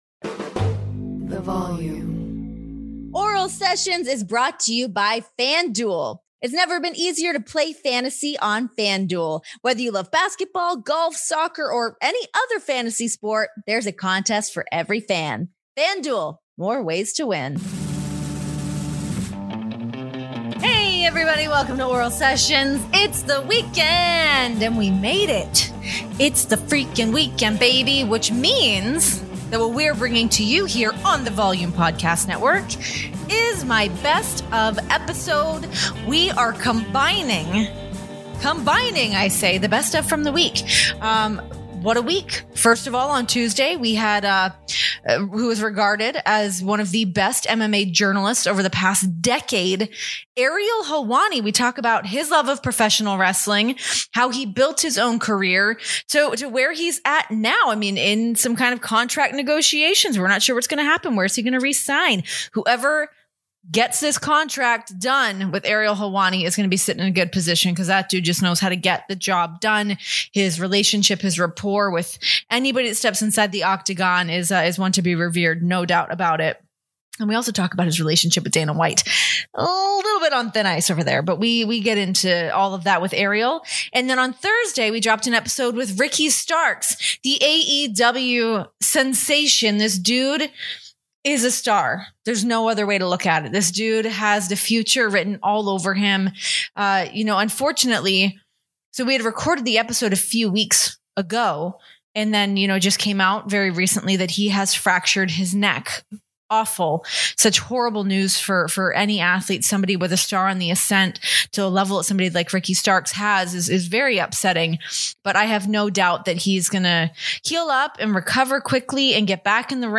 Renee had a great week of interviews on Oral Sessions and we put together the clips, in this week's "Best Of!"